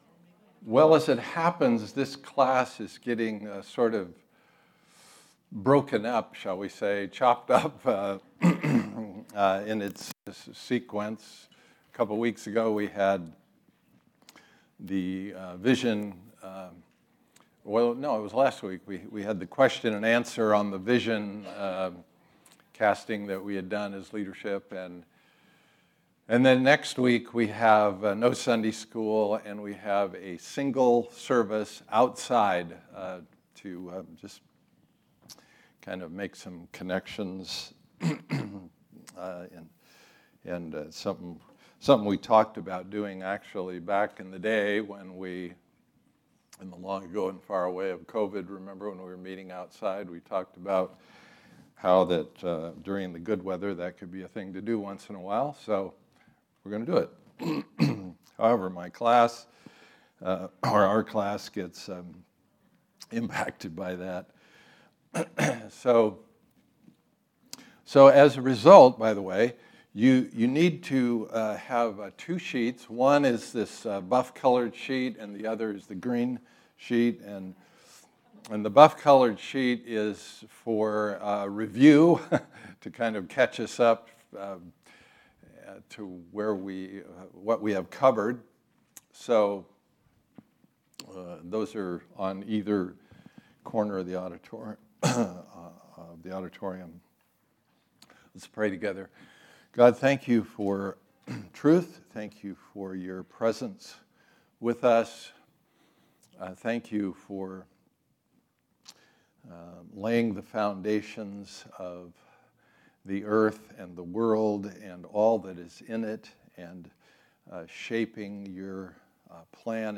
All Messages Finding a Good God in a Fractured World: Week 5 October 9, 2022 Series: Why Evil? Type: Sunday School